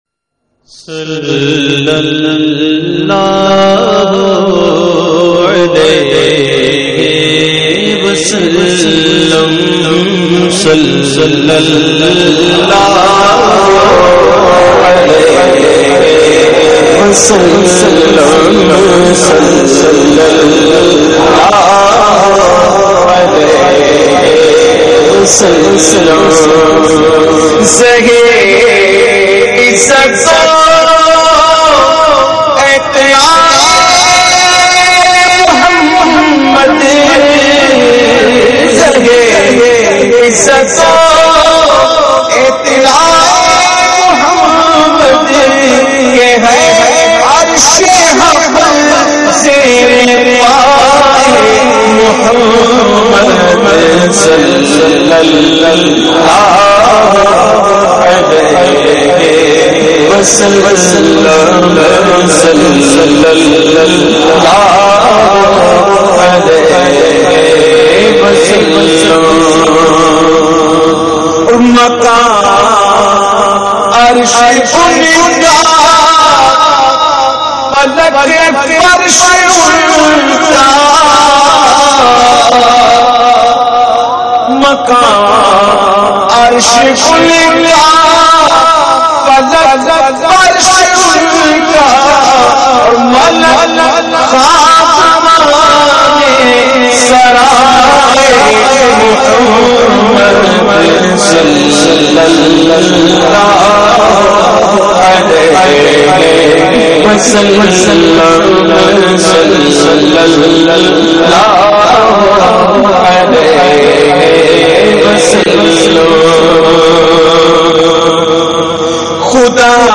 The Naat Sharif Zahe Izzat o Aitlaye Muhammad recited by famous Naat Khawan of Pakistan owaise qadri.